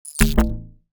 UI_SFX_Pack_61_45.wav